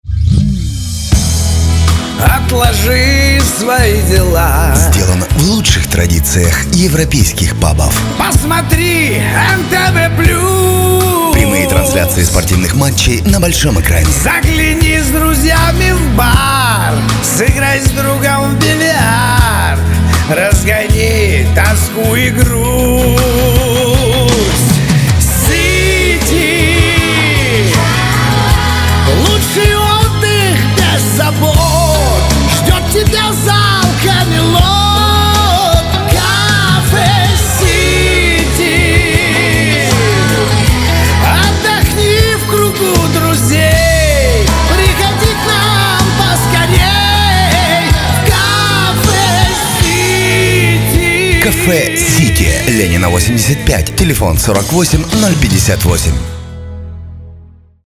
Рекламный ролик кафе City Категория: Аудио/видео монтаж